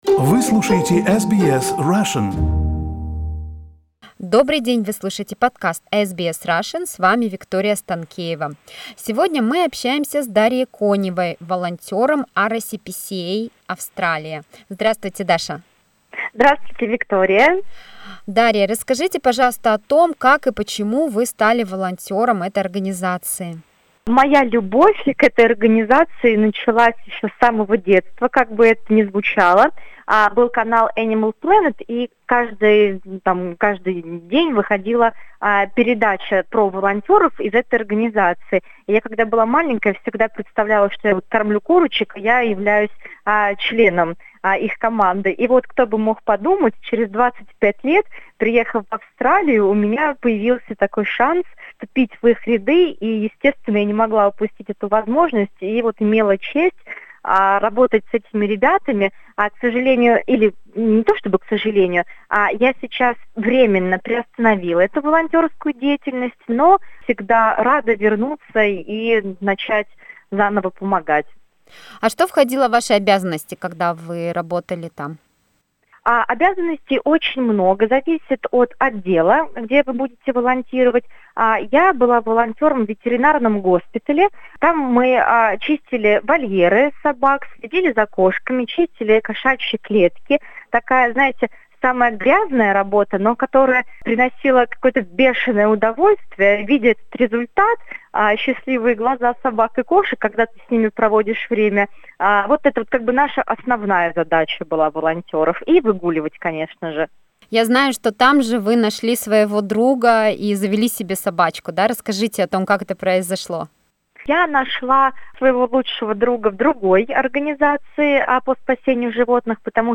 Из интервью вы узнаете: почему не стоит сразу влюбляться в выбранное животное; почему не нужно бояться брать из приюта взрослого питомца; как коронавирус повлиял на желание людей завести питомца; и многое другое.